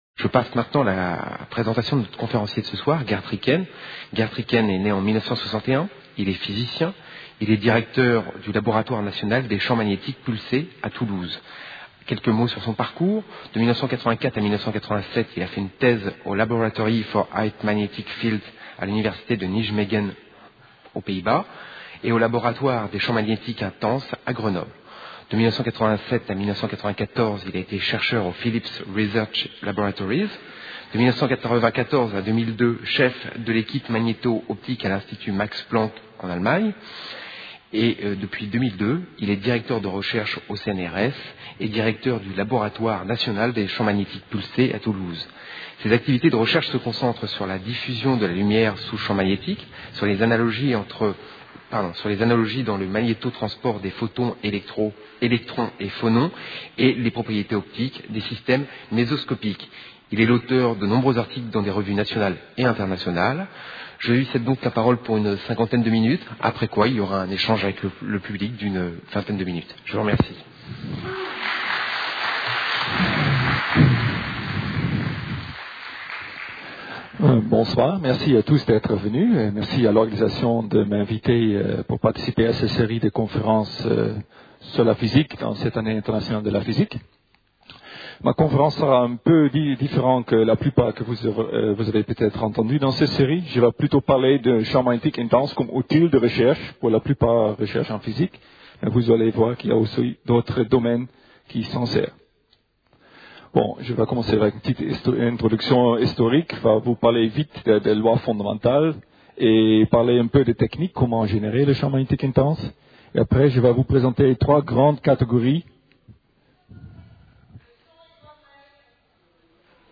Le but de cet exposé est à la fois d'expliquer la physique du champ magnétique et de démontrer l'importance des champs magnétiques intenses dans la recherche. La conférence débutera par un bref résumé de la physique des champs magnétiques, à la fois de façon historique et fondamentale.